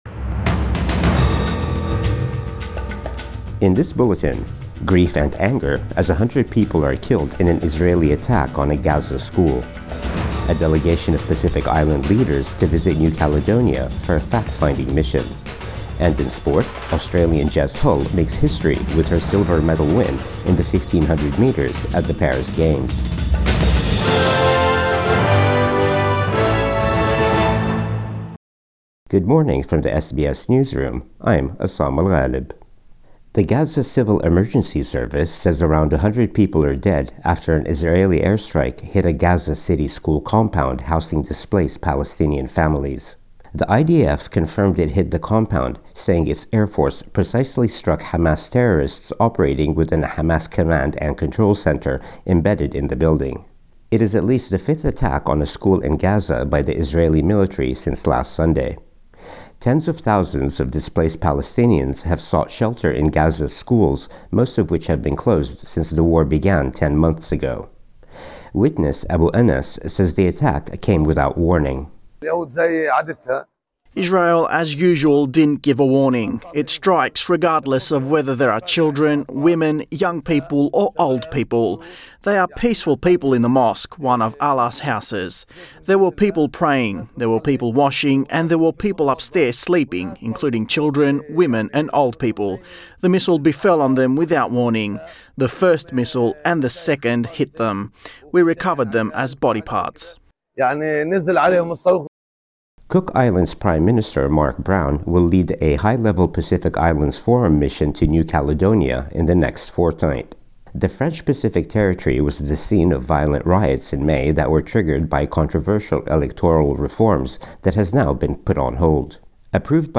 Morning News Bulletin 11 August 2024